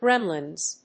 /ˈgrɛmlʌnz(米国英語), ˈgremlʌnz(英国英語)/